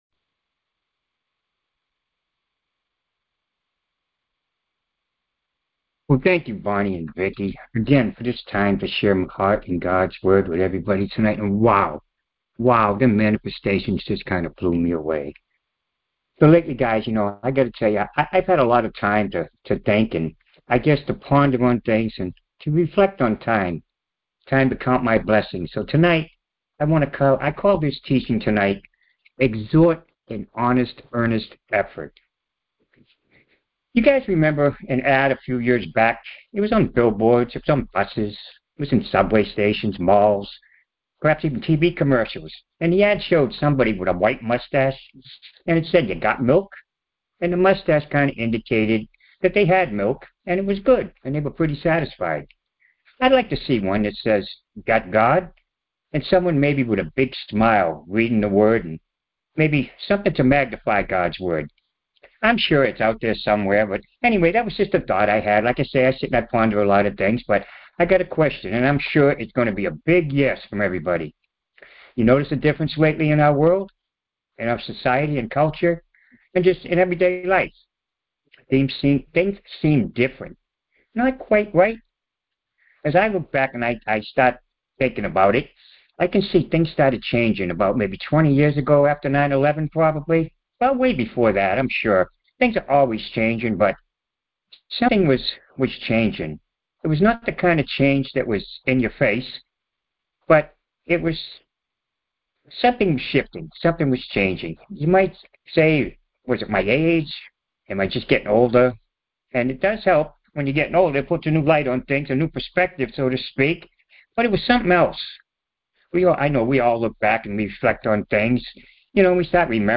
Details Series: Conference Call Fellowship Date: Thursday, 09 April 2026 Hits: 74 Scripture: 2 Timothy 2:15 Play the sermon Download Audio ( 8.78 MB )